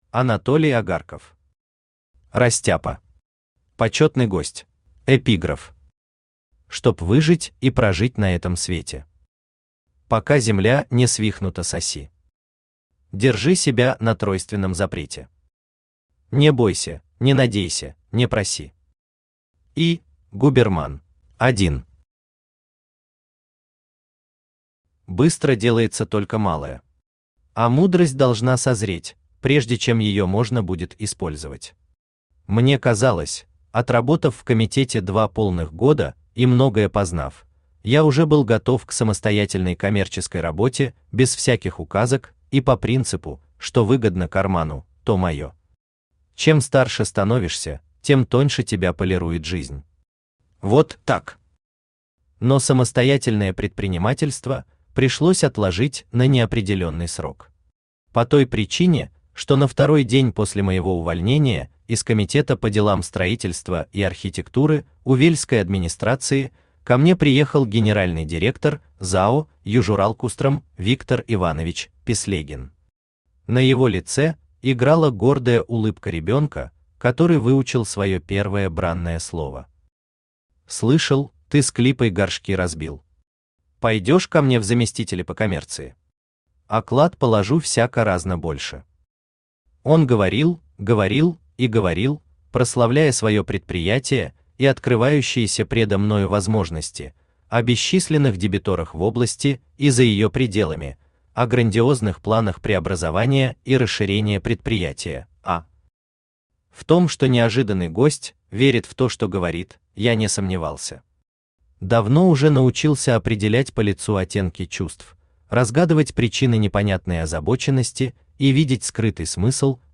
Аудиокнига Растяпа. Почетный гость | Библиотека аудиокниг
Почетный гость Автор Анатолий Агарков Читает аудиокнигу Авточтец ЛитРес.